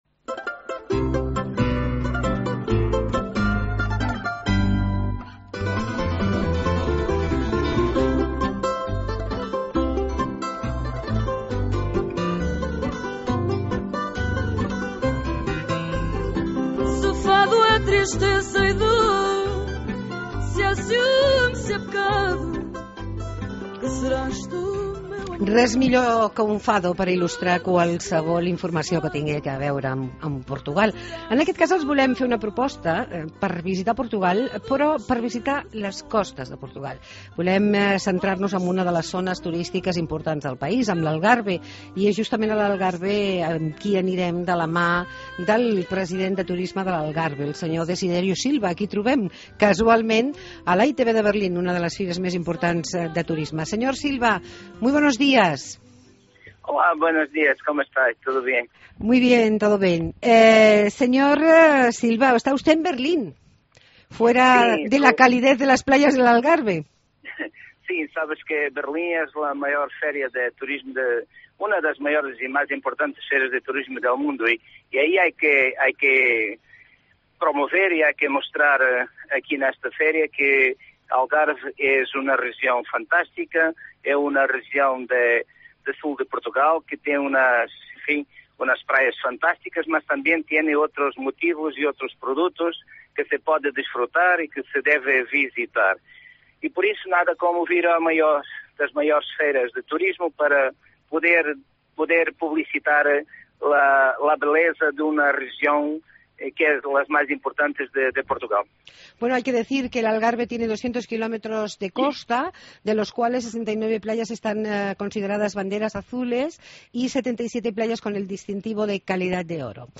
Entrevista con el Presidente de Turismo de Algarve